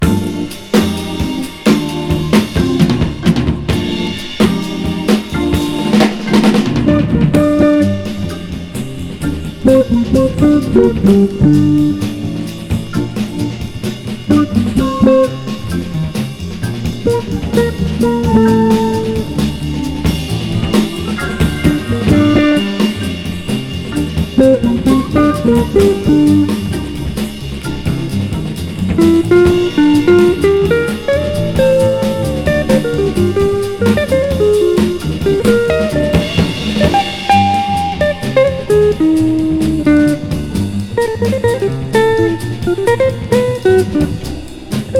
ギター、オルガンの他、フルート、ベース、ドラムといった編成。"
グルーヴィーさ有り、ドラマチックさ有り、通して抑揚の効いた楽曲と演奏に思わず情景が浮かびます。